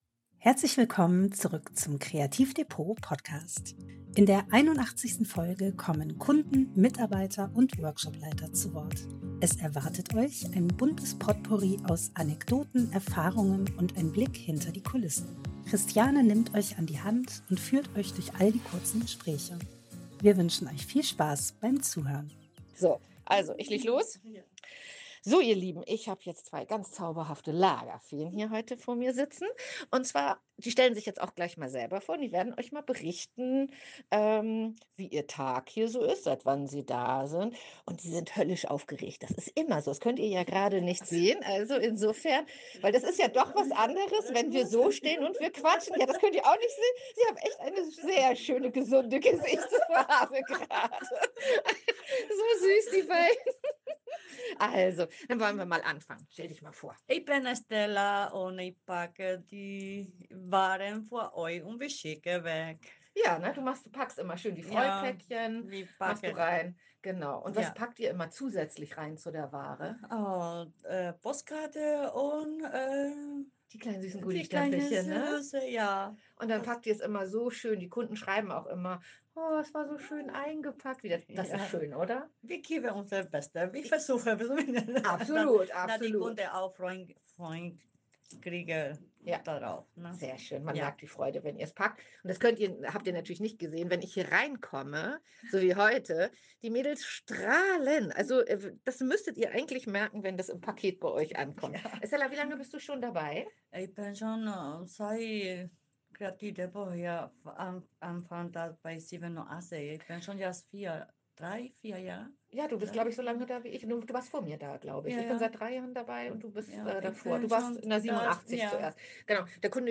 In der 81. Folge kommen Kunden, Mitarbeiter und Workshopleiter zu Wort. Es erwartet euch ein buntes Potpourri aus Anekdoten, Erfahrungen und ein Blick hinter die Kulissen.